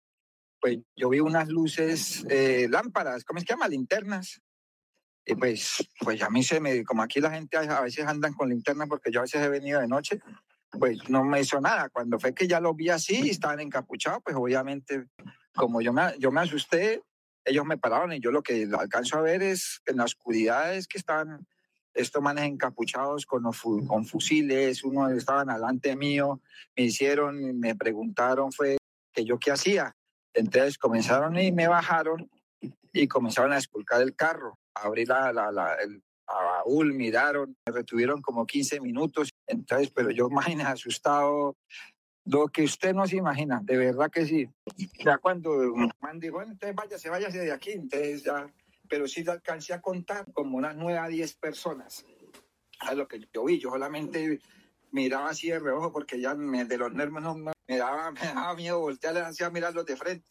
El testimonio se conoció en diálogo con Caracol Radio, en medio de la alerta por seguridad en las vías del departamento.
Ciudadano en la vía San Vicente de Chucurí - Bucaramanga